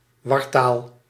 Ääntäminen
UK : IPA : /ˌabɹakəˈdabrə/